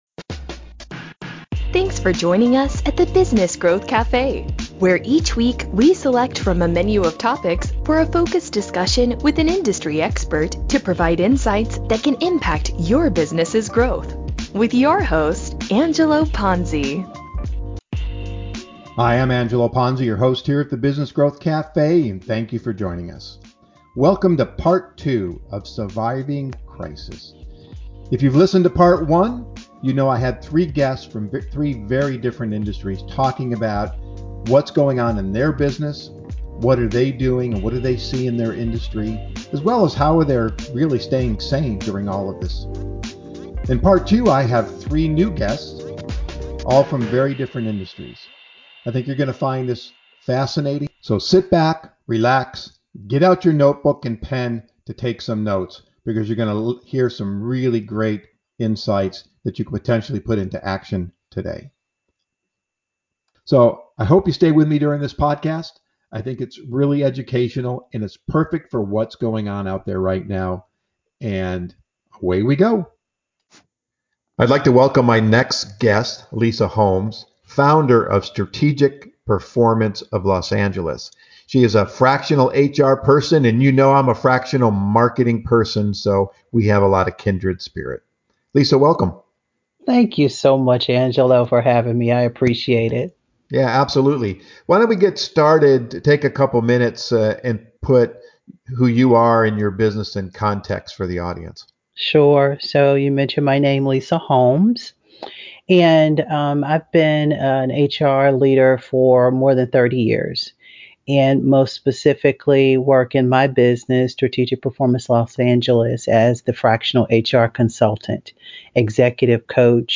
In Part 2, I continue the conversation with 3 new business leaders from different industries to gain their perspective on how they and others are surviving and preparing for tomorrow.